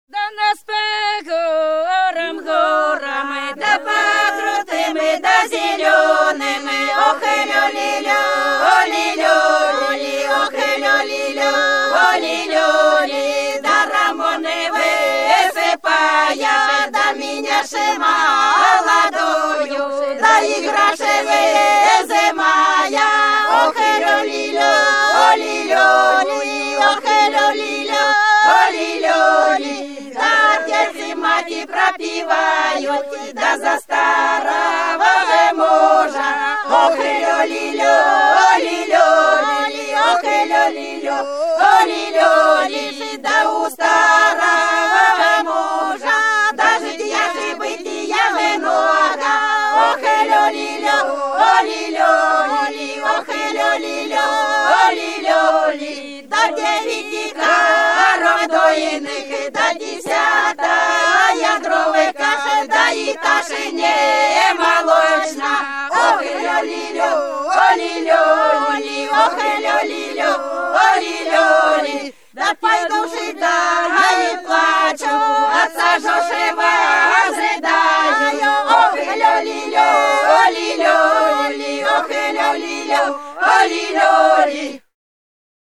Вдоль по улице пройду (Бутырки Репьёвка) 023. В нас по горам, горам — хороводная песня.